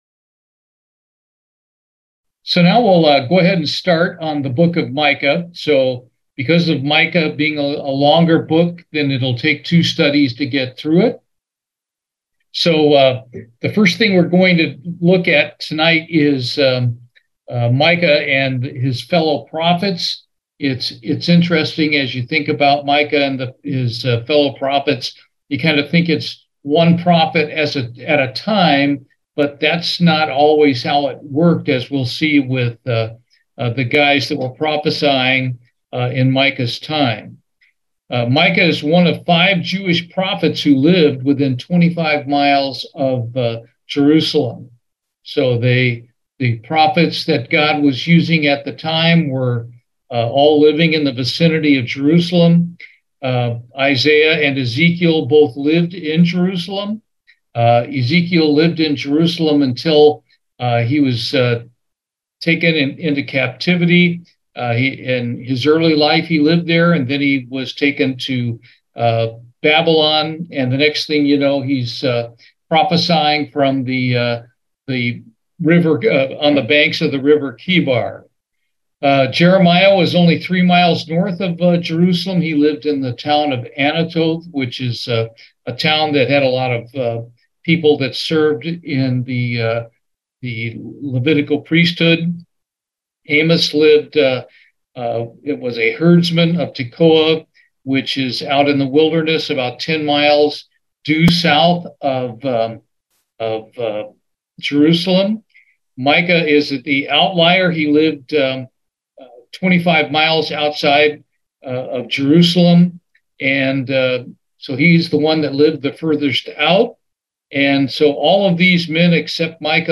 Bible Study, Micah, Part 1